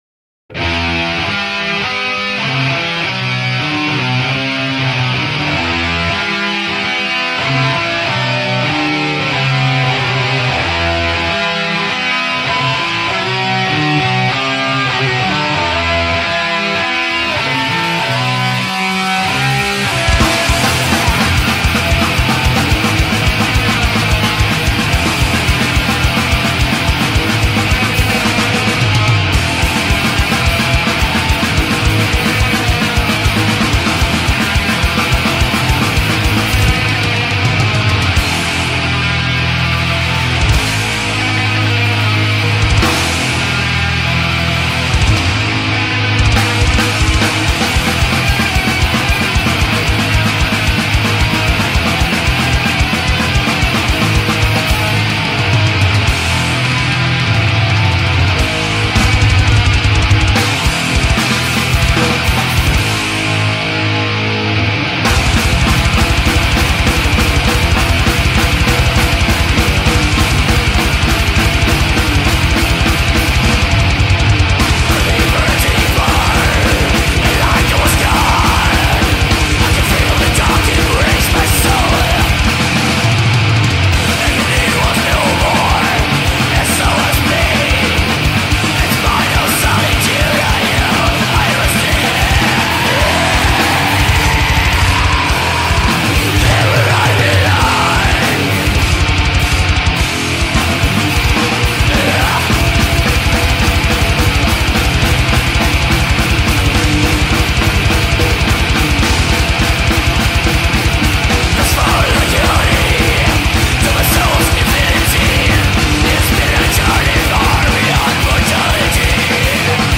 بلک متال
black metal